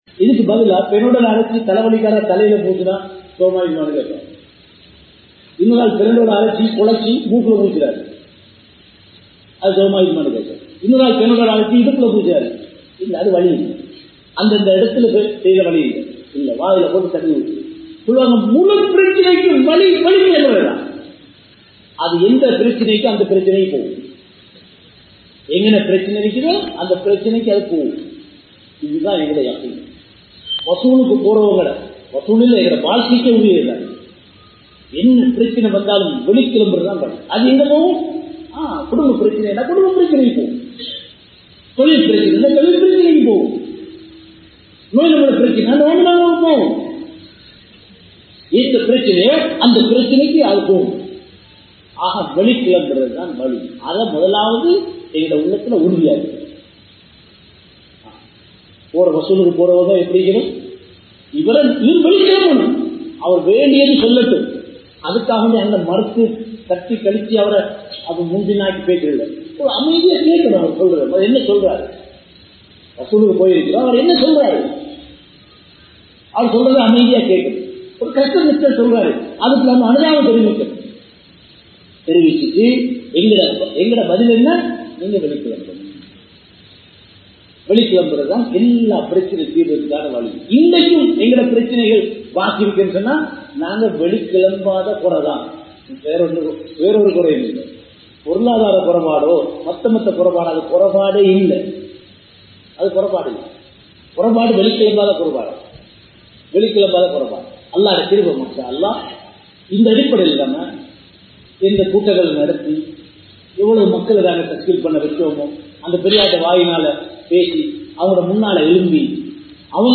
Dawathudaiya Usool(தஃவத்துடைய உஸூல்) | Audio Bayans | All Ceylon Muslim Youth Community | Addalaichenai